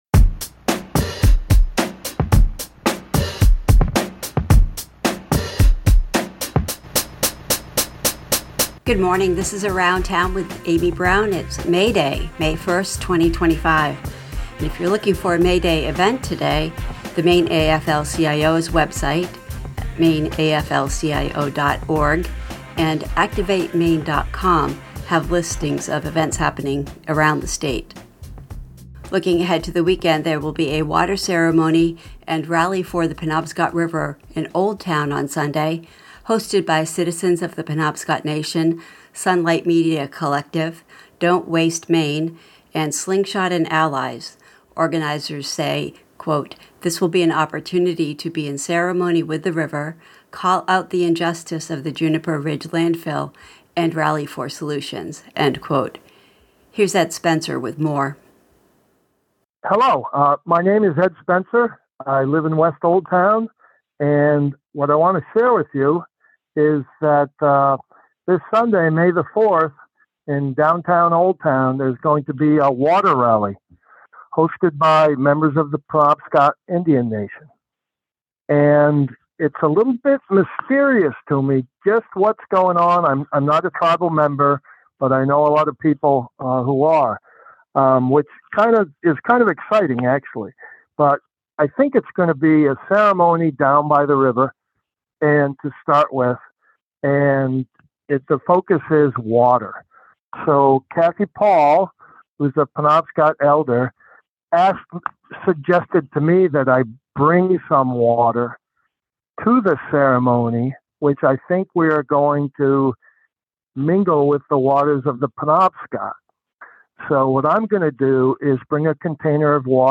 Around Town 5/1/25: Local News, Culture and Events | WERU 89.9 FM Blue Hill, Maine Local News and Public Affairs Archives